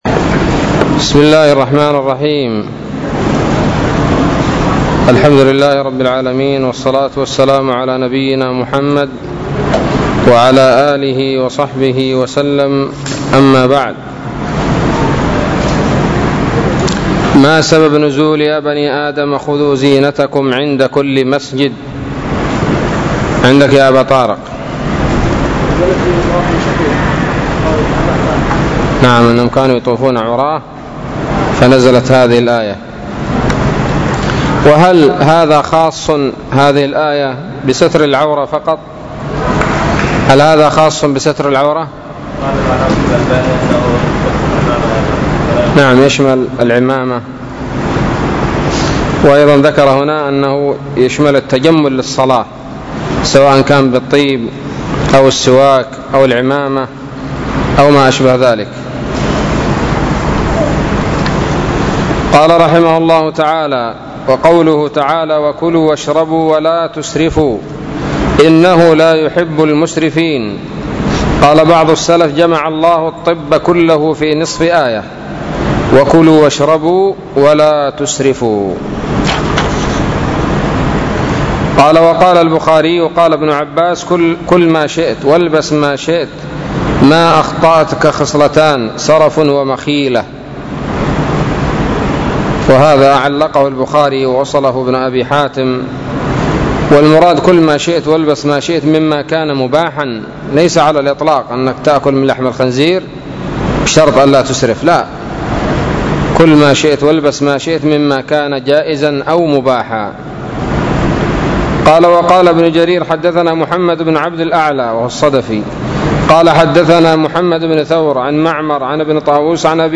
الدرس الثاني عشر من سورة الأعراف من تفسير ابن كثير رحمه الله تعالى